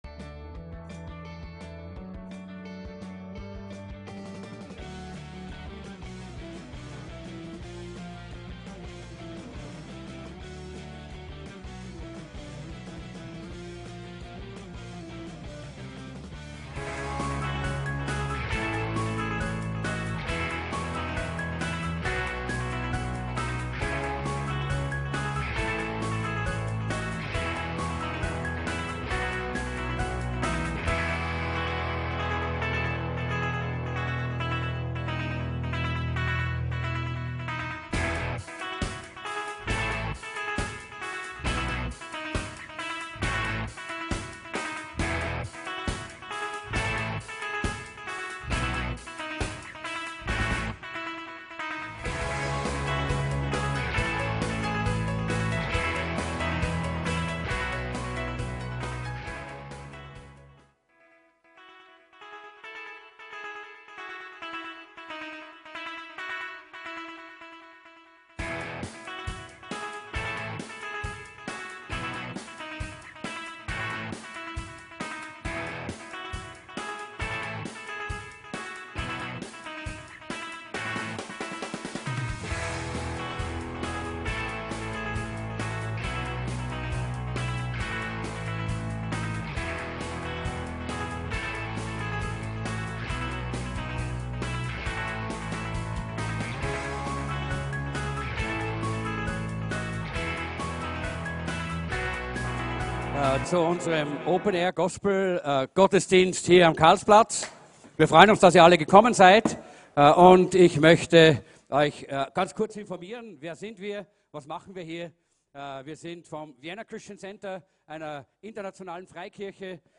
OPEN AIR GOSPEL GOTTESDIENST - WIEN -KARLSPLATZ